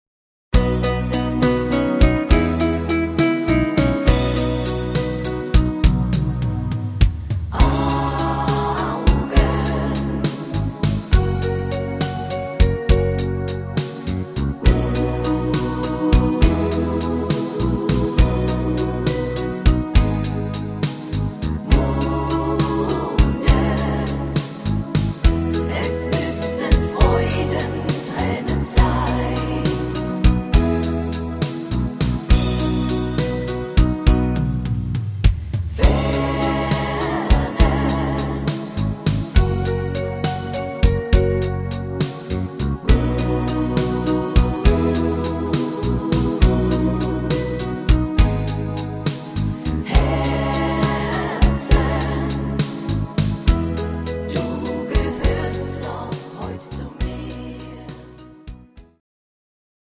Rhythmus  Slow
Art  Deutsch, Schlager 70er